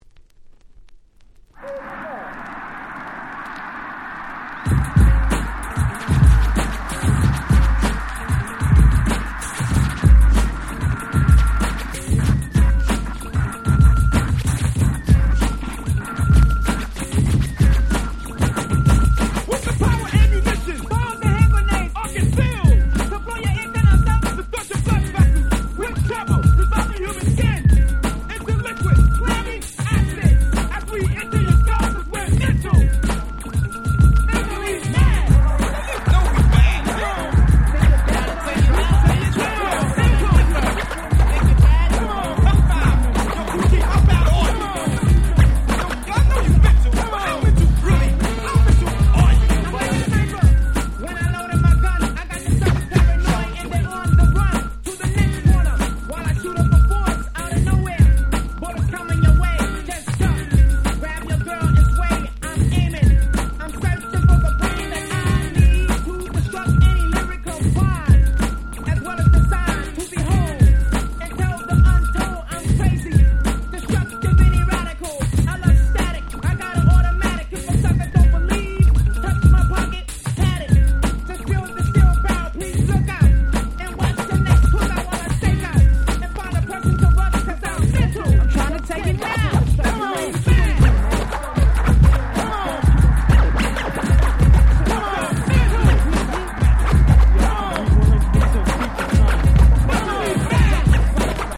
87' Hip Hop Super Classics !!
ミドルスクール Middle School 80's